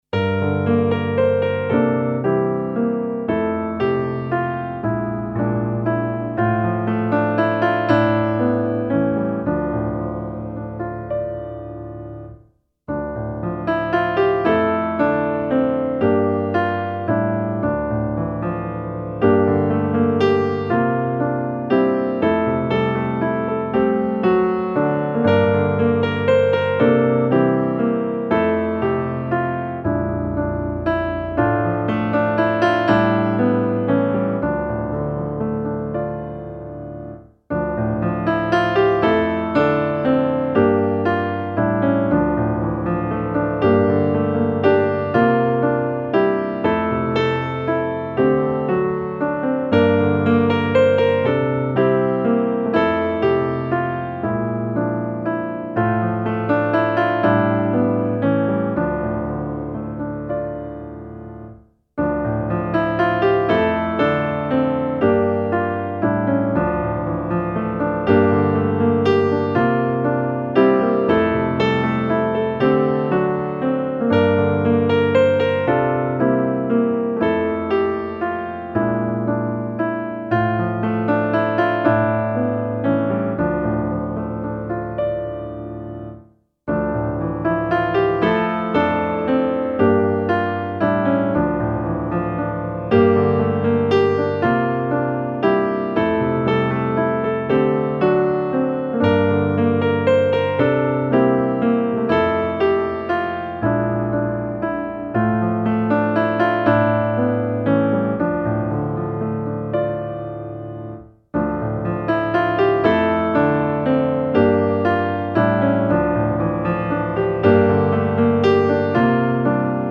Lågorna är många - musikbakgrund
Musikbakgrund Psalm